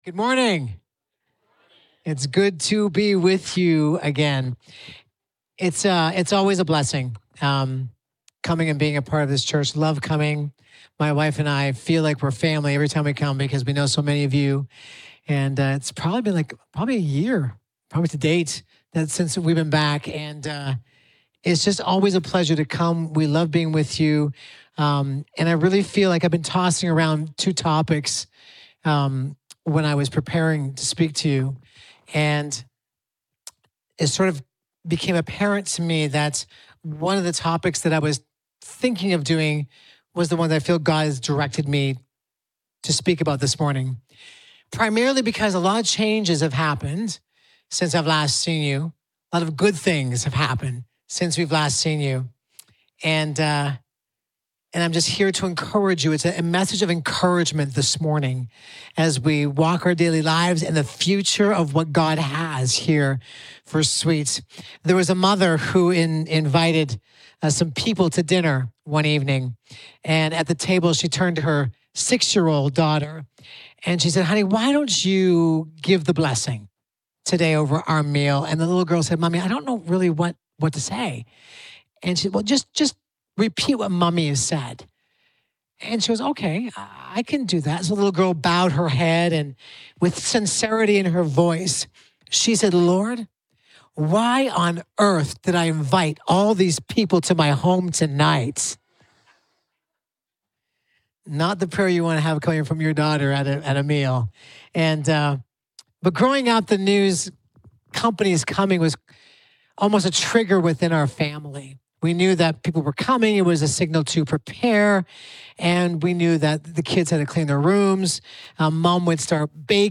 From Series: "Guest Preachers"